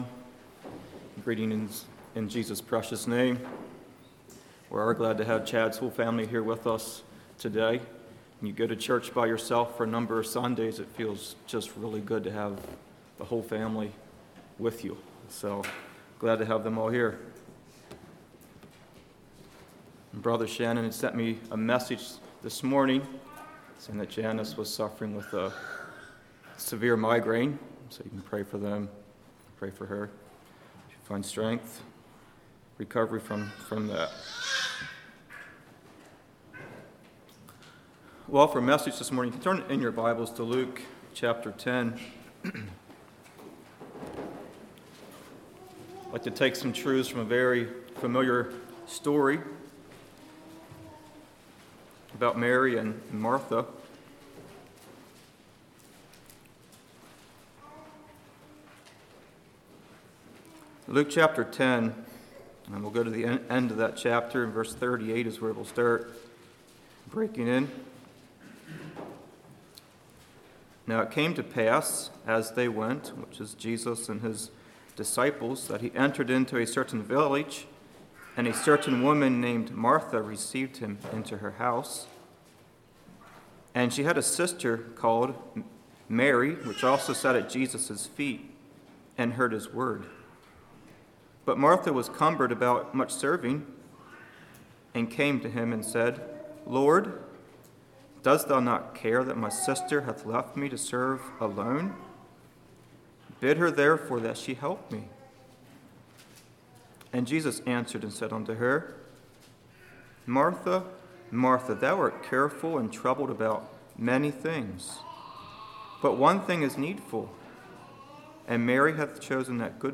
Website of Pilgrim Conference | Christian Sermons, Anabaptist e-Literature, Bible Study Booklets | Pilgrim Ministry
Play Now Download to Device The Sin Of Self-Pity Congregation: Kirkwood Speaker